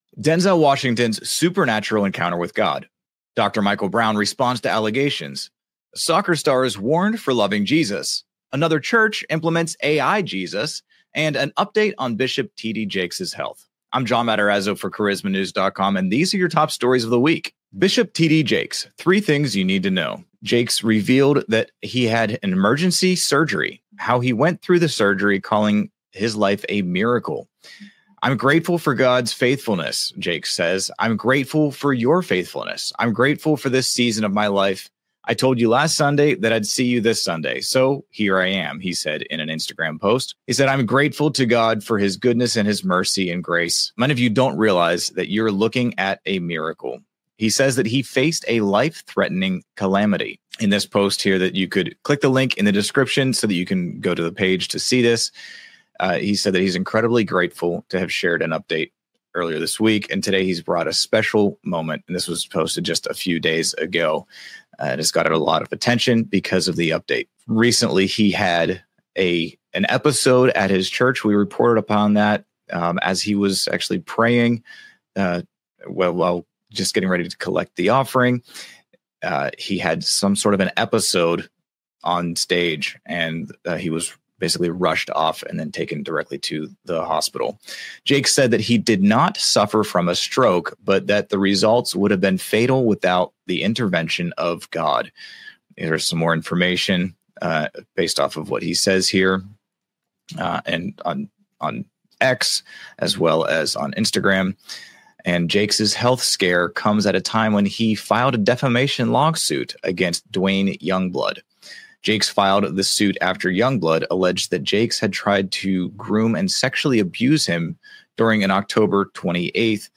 News stories and interviews well told from a Christian perspective.